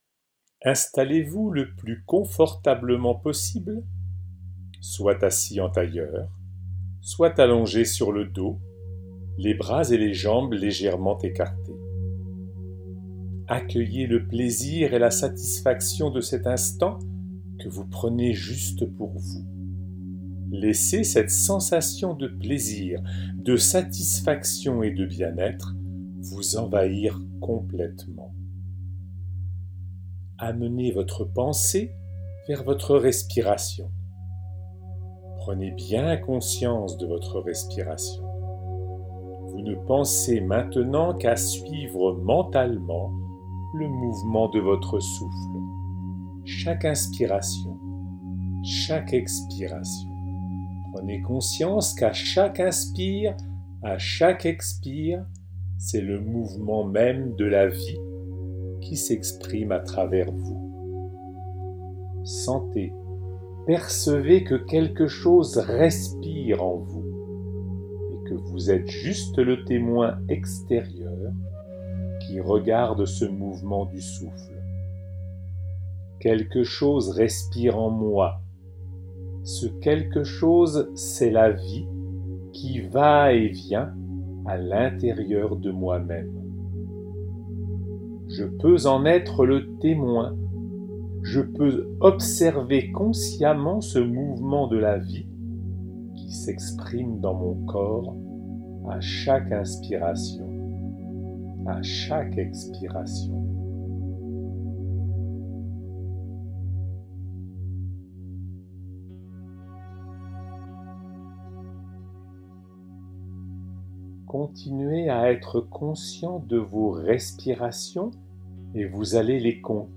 Une relaxation guidée (20') tirée du livre de Joëlle MAUREL - Ed. Guy TrédanielCliquer sur l'image ci-dessous pour l'écouter :